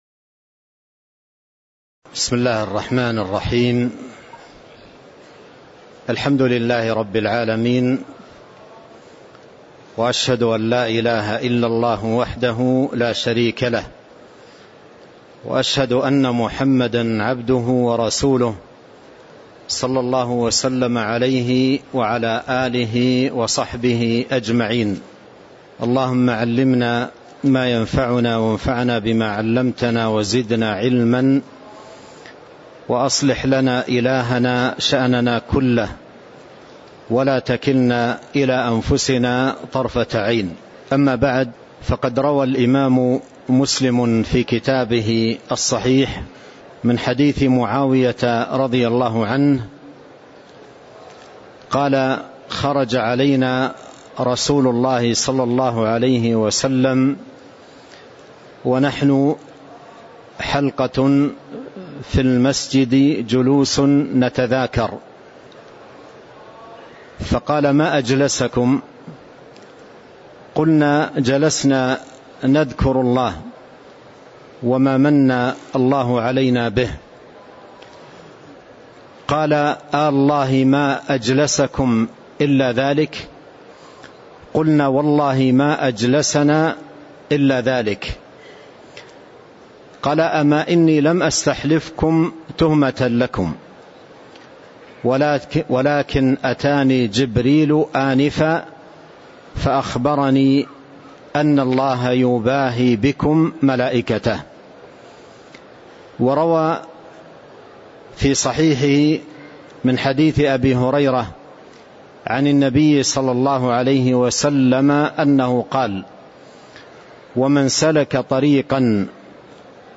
تاريخ النشر ٥ ربيع الثاني ١٤٤٤ هـ المكان: المسجد النبوي الشيخ